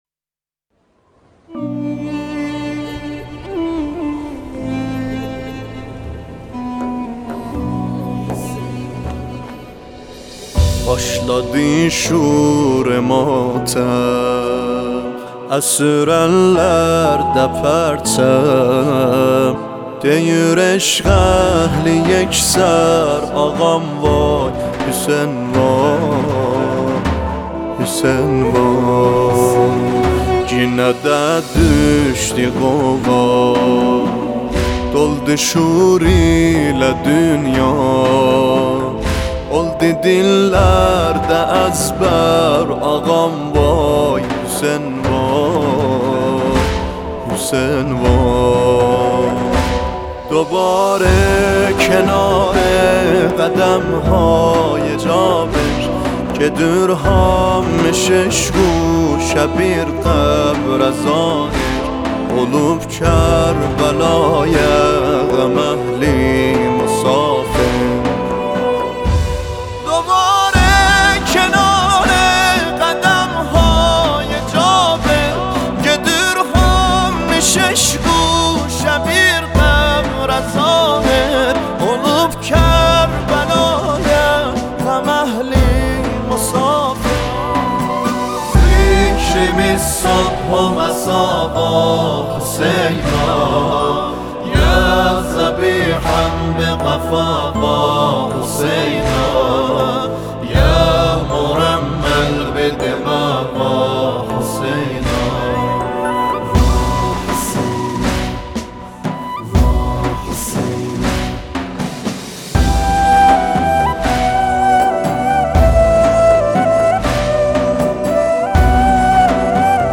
مداحی واحسینا
به مناسبت اربعین حسینی علیه السلام